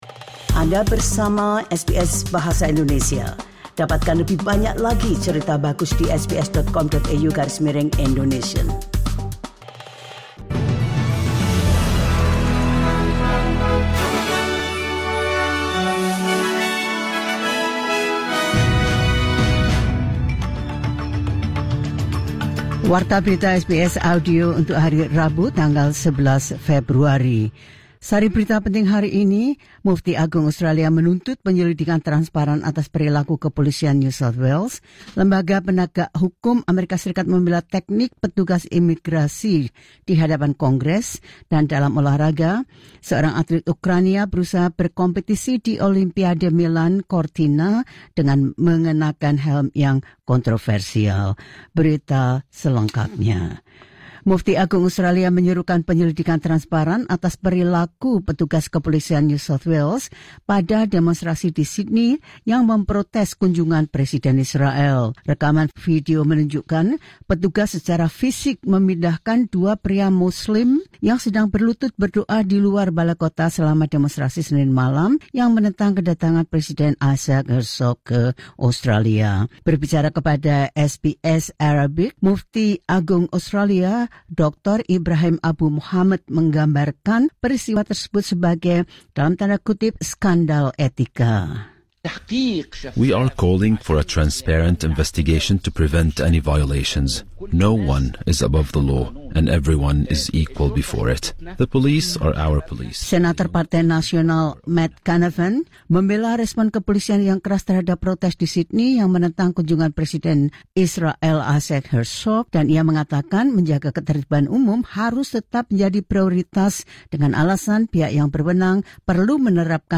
The latest news SBS Audio Indonesian Program – Wed 11 February 2026.